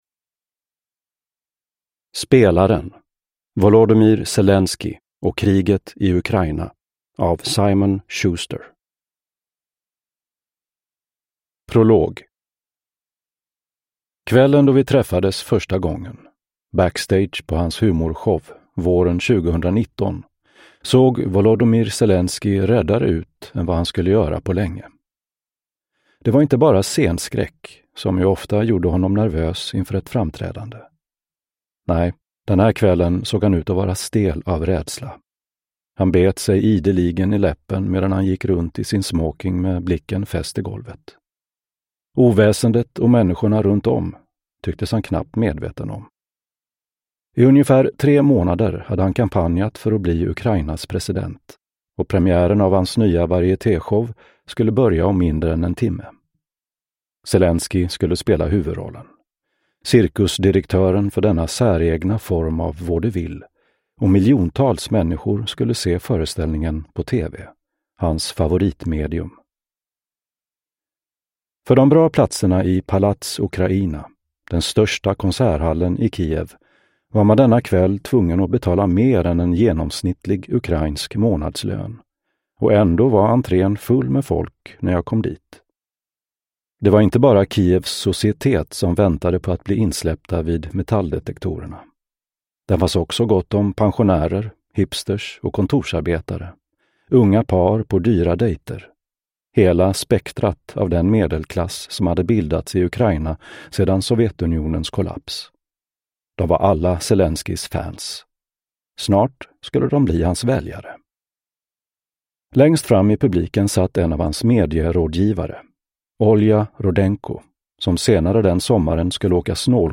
Spelaren : Volodymyr Zelenskyj och kriget i Ukraina – Ljudbok